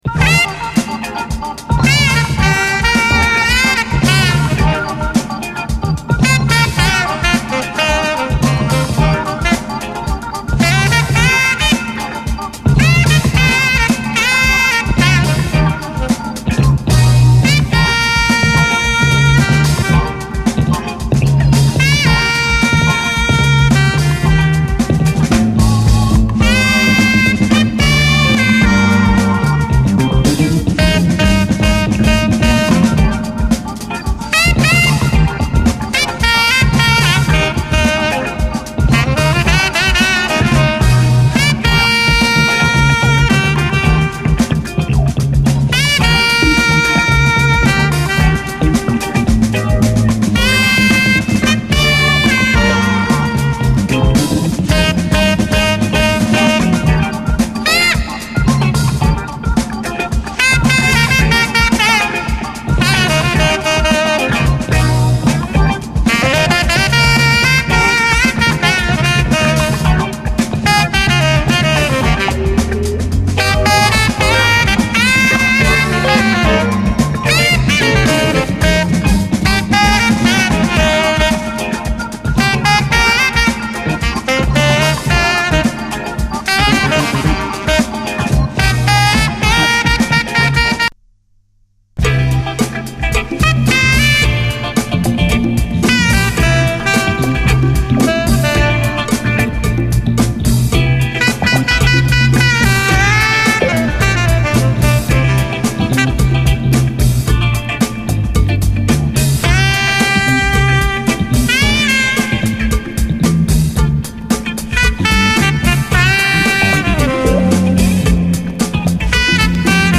JAZZ FUNK / SOUL JAZZ, JAZZ
ネタ感ある切れ味鋭いジャズ・ファンク
ワウ・ギターやモコモコ＆グニョグニョのベース・ラインがイカしてます！